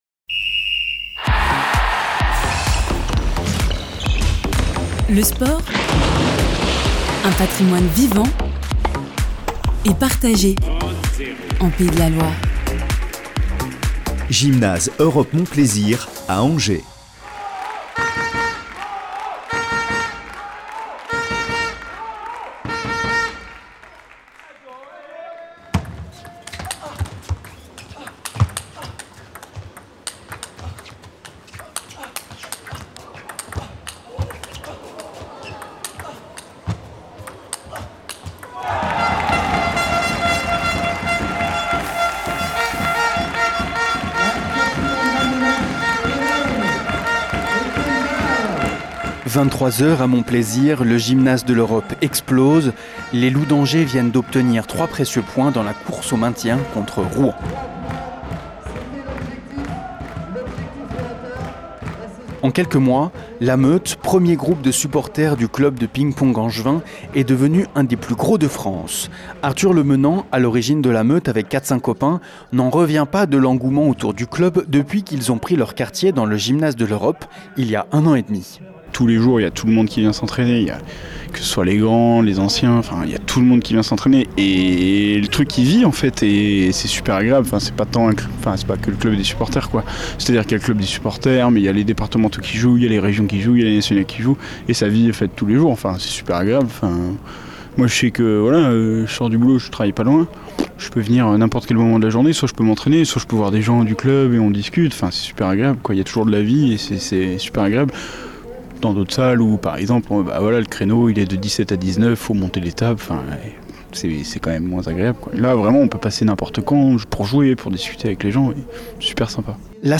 Radio Campus Angers s’est rendu sur place.